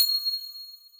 drum-hitwhistle2.wav